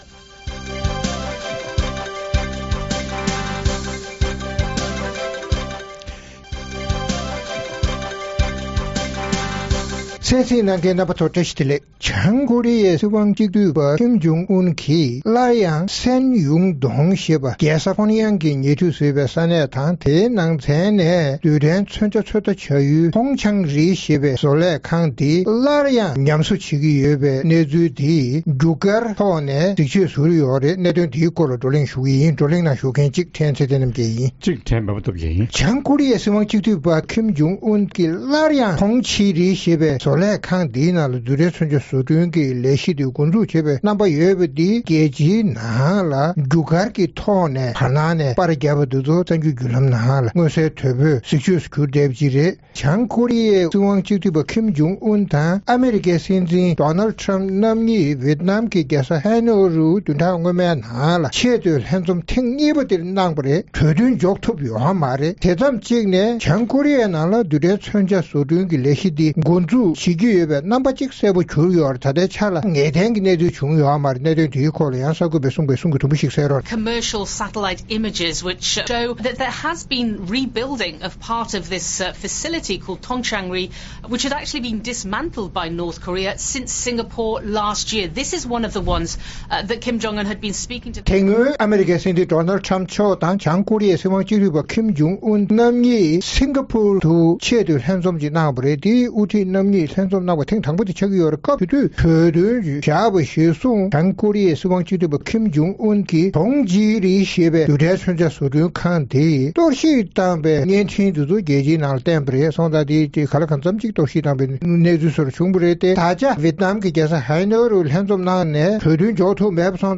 རྩོམ་སྒྲིག་པའི་གླེང་སྟེགས་ཞེས་པའི་ལེ་ཚན་ནང་། ཉེ་ལམ་ཨ་རིའི་སྲིད་འཛིན་ Donald Trump དང་། བྱང་ཀོ་རི་ཡའི་དབུ་ཁྲིད་Kim Jung Un གཉིས་ Vietnam གྱི་རྒྱལ་ས་Hanoiརུ་ཆེས་མཐོའི་ལྷན་འཛོམས་གནང་ཡང་གྲོས་ཆོད་འཇོག་གནང་ཐུབ་མེད་པ་དང་། བྱང་ཀོ་རི་ཡས་སླར་ཡང་མེ་ཤུགས་འཕུར་མདེལ་ཚོད་བལྟ་བྱ་ཡུལ་ཉམས་གསོ་དང་རྒྱ་སྐྱེད་བྱེད་བཞིན་པའི་བཅོས་མའི་རྒྱུ་སྐར་གྱི་འདྲ་པར་རྒྱལ་སྤྱིའི་གསར་འགྱུར་བརྒྱུད་ལམ་ནང་ཐོན་བཞིན་པའི་གནད་དོན་སོགས་ཀྱི་སྐོར་རྩོམ་སྒྲིག་འགན་འཛིན་རྣམ་པས་བགྲོ་གླེང་གནང་གསན་རོགས་གནང་།